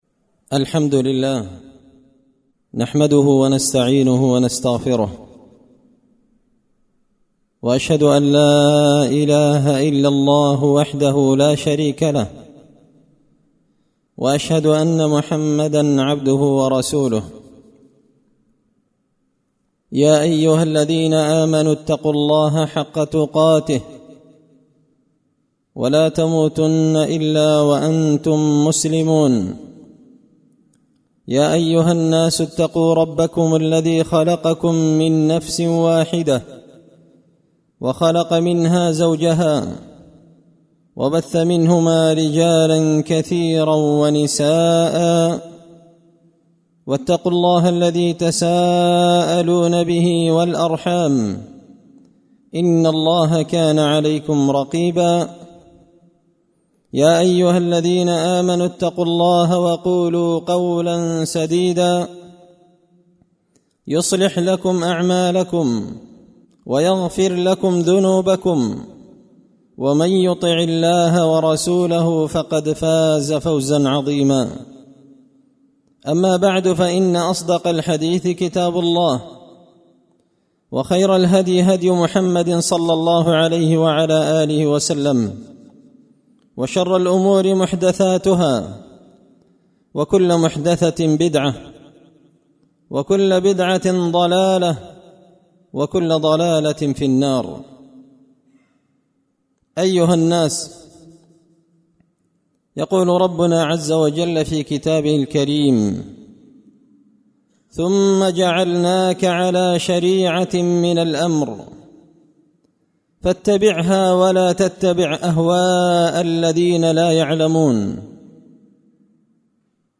خطبة جمعة بعنوان – مخالفة المشركين
دار الحديث بمسجد الفرقان ـ قشن ـ المهرة ـ اليمن